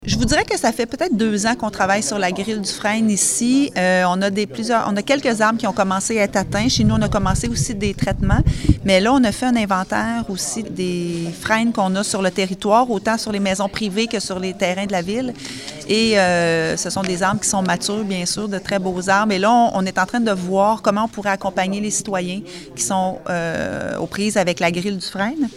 Écouter la mairesse de Nicolet, Geneviève Dubois`: